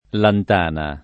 lantana [ lant # na ]